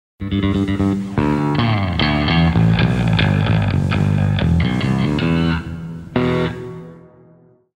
Geddy's improv-bass riff